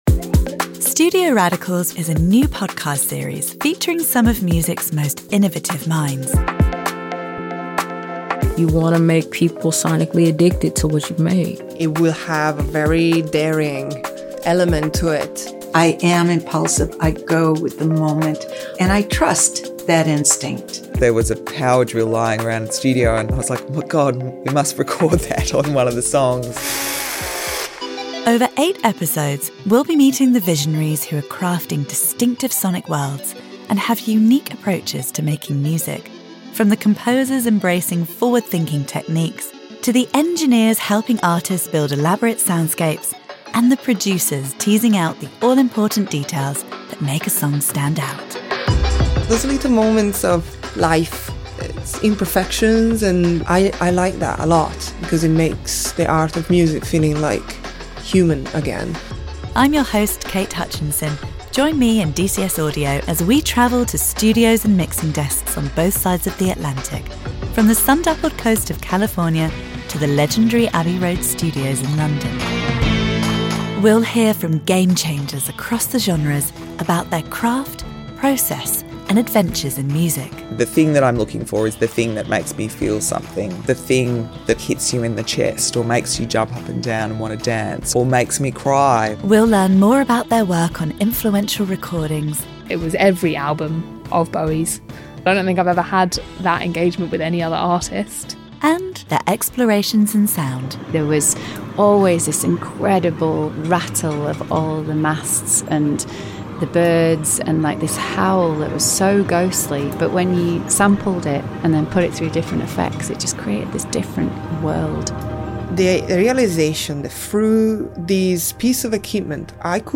🎧 立即收聽系列預告片： 要製作一張暢銷專輯需要哪些要素？